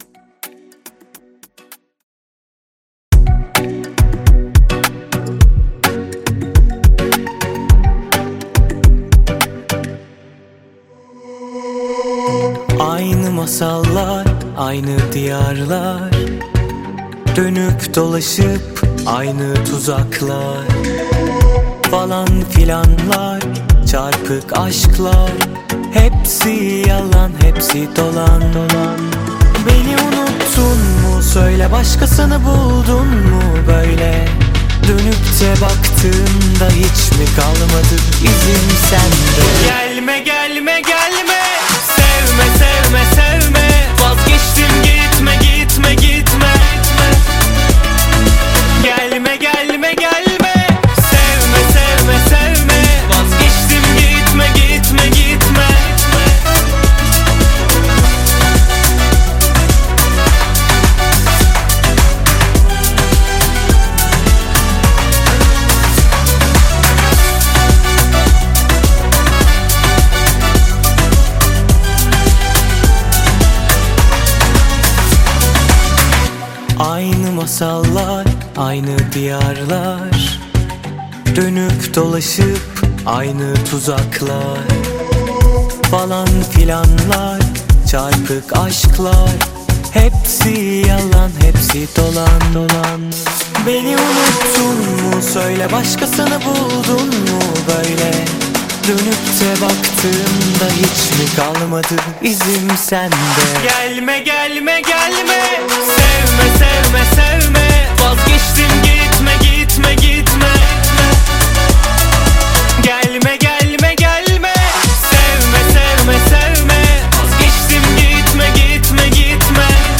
• Категория: Турецкие песни